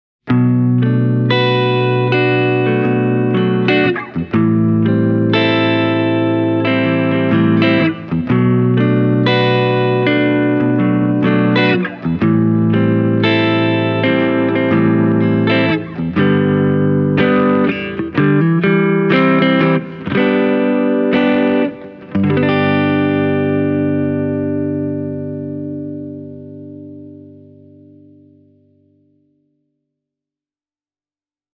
The Harlow stays clear and clean, even when you play a neck humbucker (I’m using my Hamer USA Studio Custom):